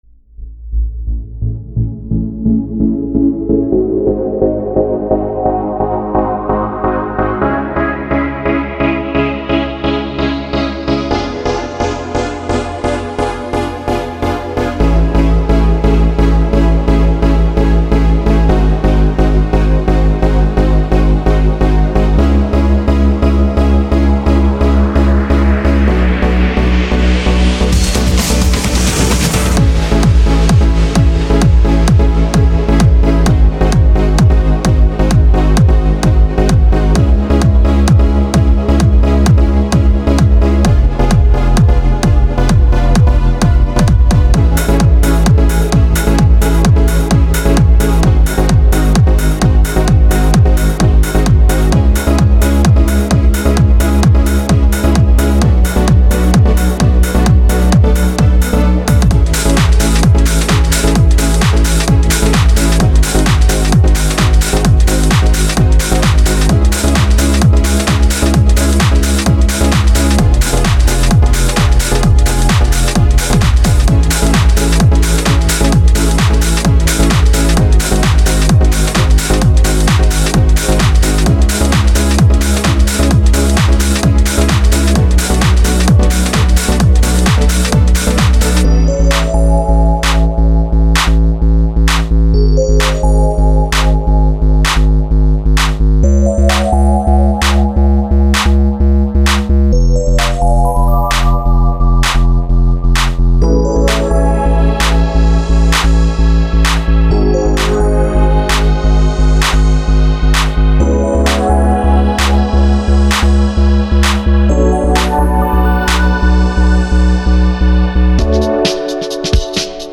wonderful mood, loved the breakdown